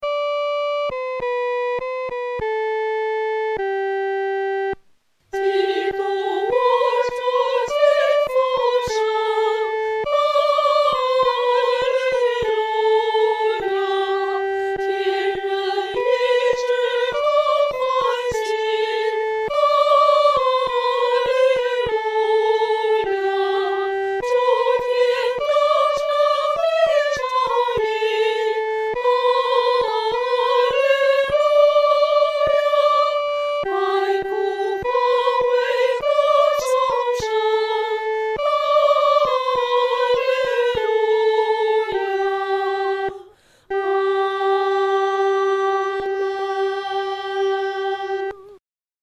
合唱
女高
本首圣诗由网上圣诗班录制